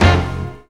JAZZ STAB 21.wav